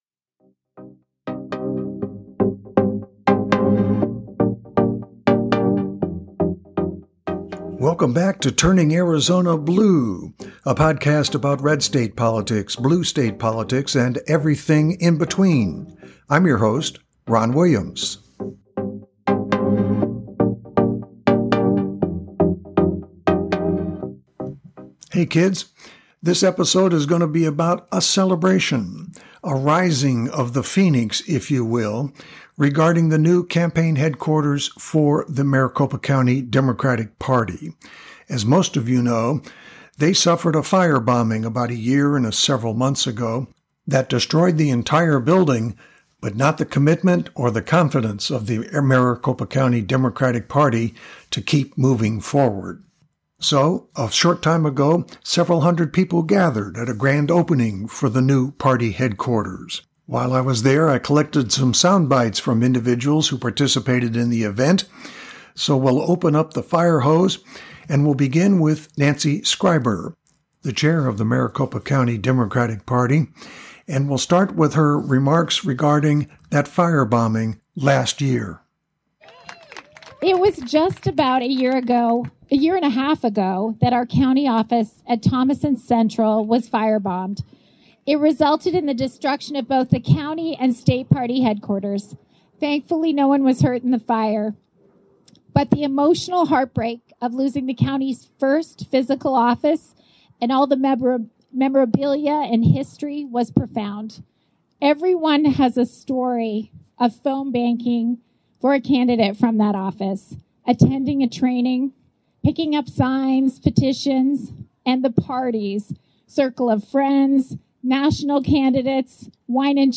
We captured sound bites from almost a dozen attendees about what the event signified for Maricopa Democrats going forward.